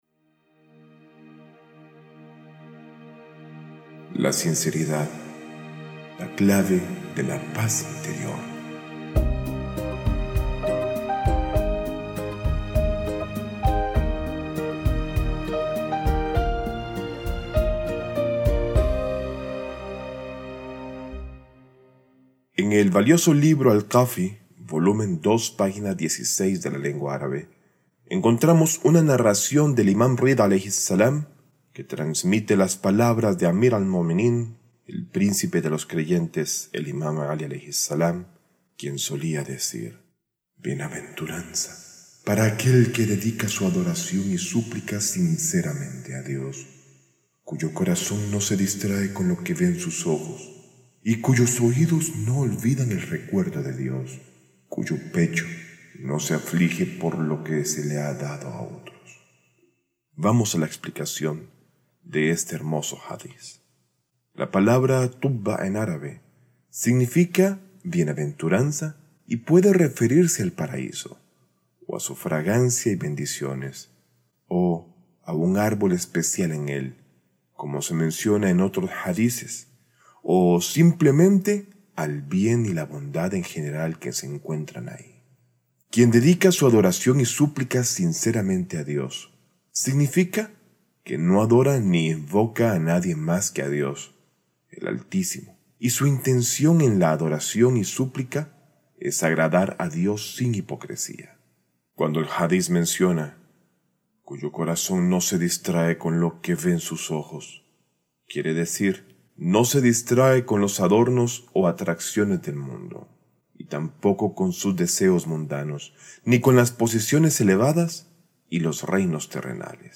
Este video es la primera lección de la serie "Voces de la Fe", una colección de reflexiones espirituales basadas en enseñanzas auténticas.